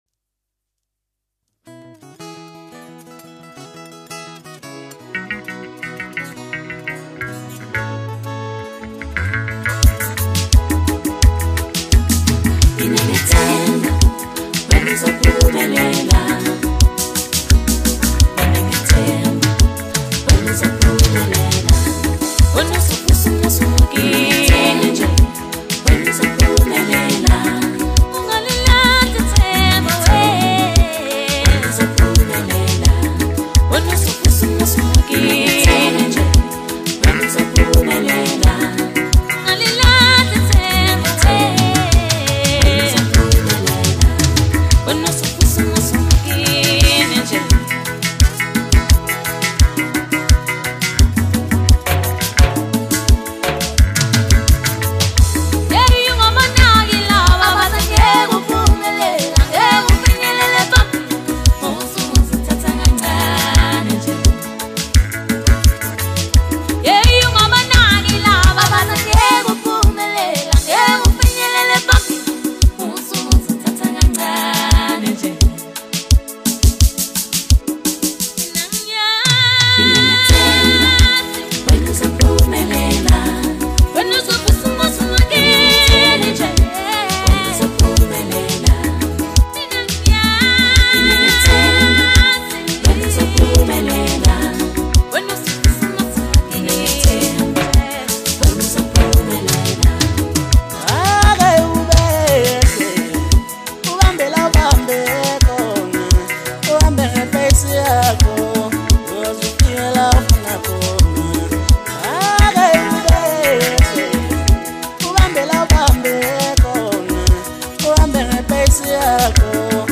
Home » Maskandi » DJ Mix » Hip Hop
South African singer-songsmith